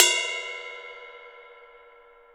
CUP  1.wav